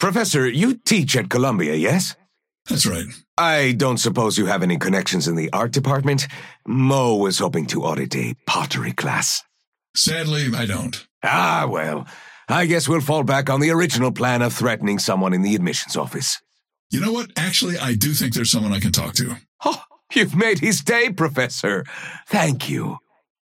Dynamo and Krill conversation 1